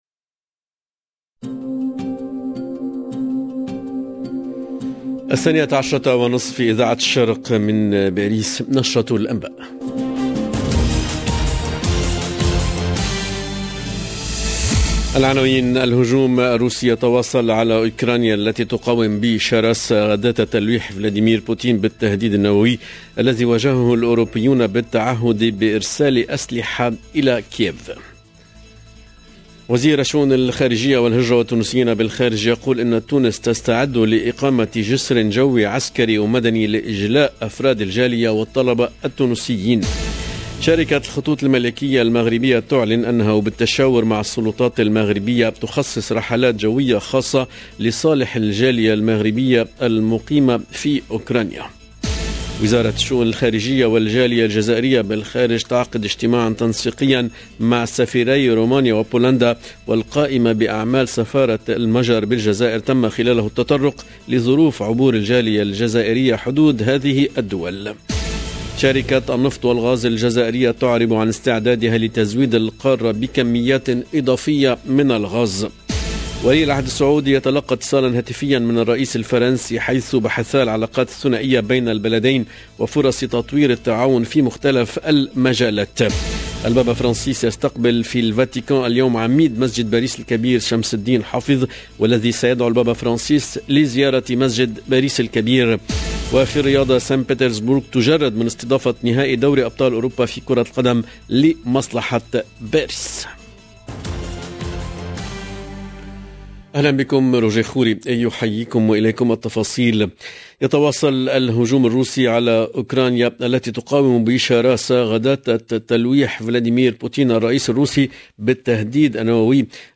LE JOURNAL DE MIDI 30 EN LANGUE ARABE DU 28/02/22